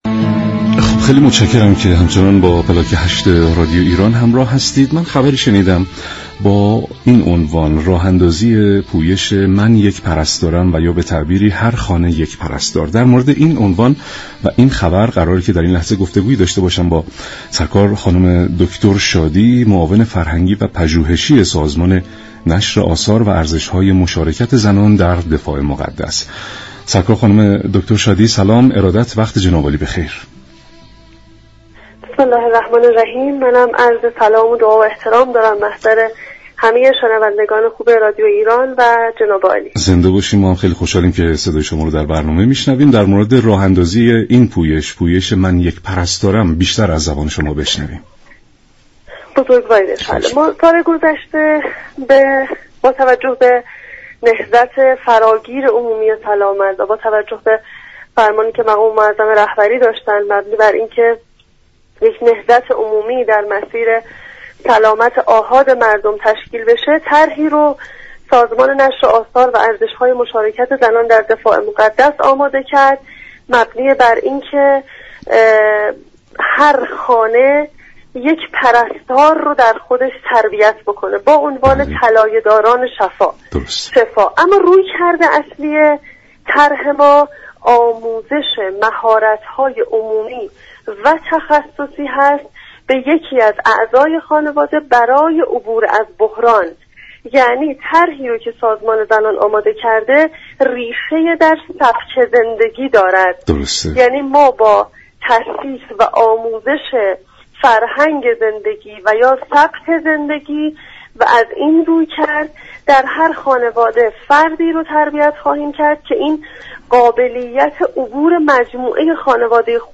برنامه پلاك هشت شنبه تا چهارشنبه هر هفته ساعت 13:30 از رادیو ایران پخش می شود.